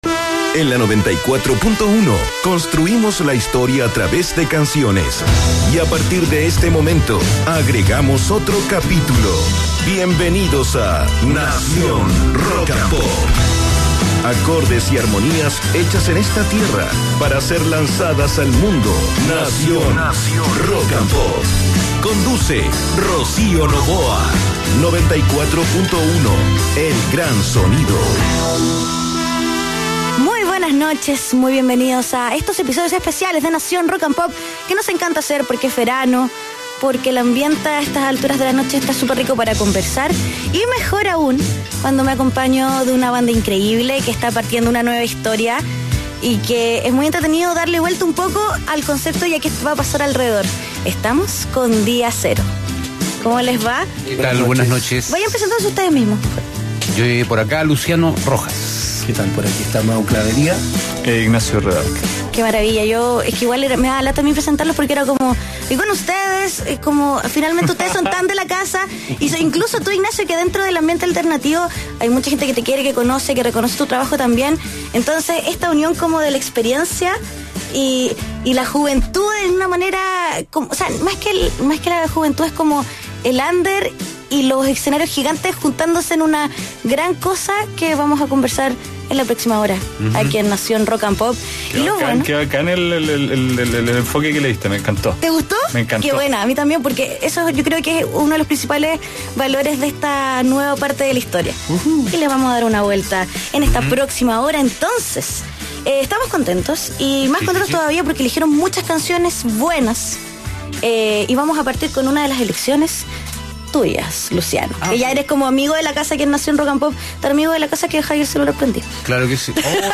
| diacero, Entrevista, Música, musica chilena, NacionRP 3 de febrero de 2017 7:34 PM Música 24/7, noticias, actualidad, concursos